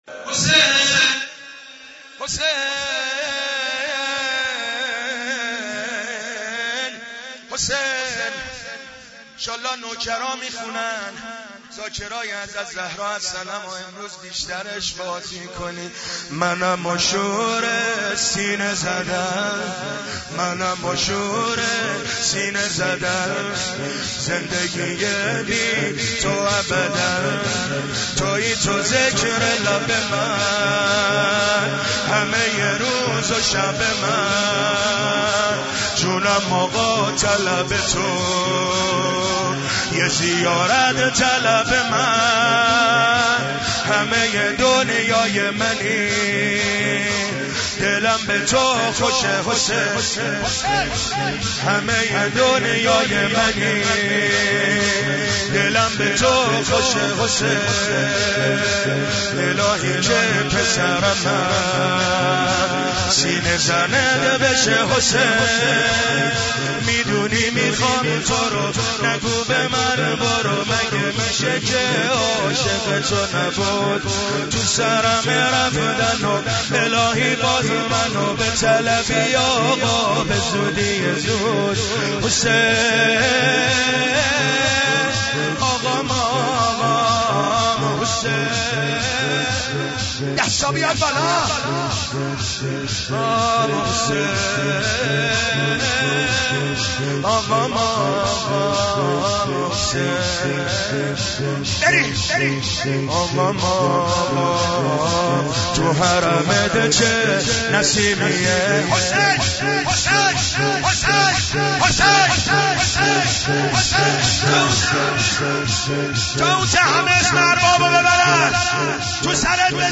روضه
سینه زنی شور